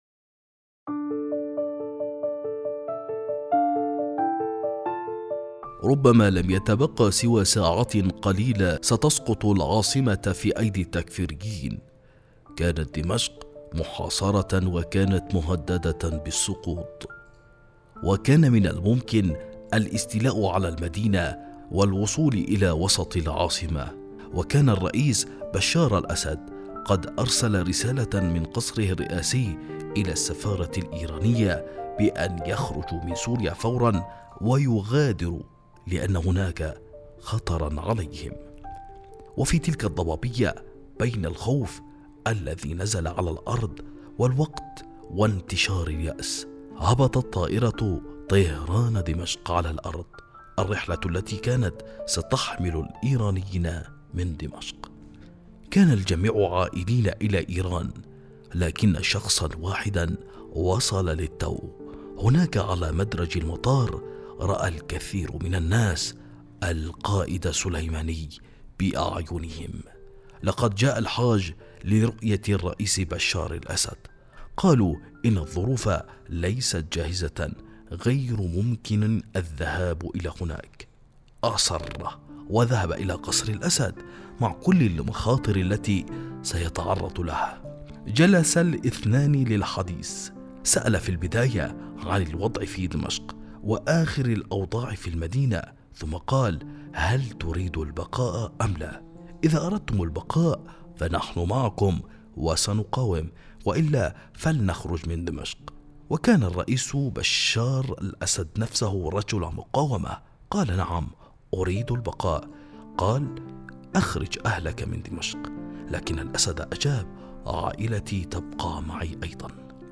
الراوي: مجتبى أماني، سفير إيران السابق في مصر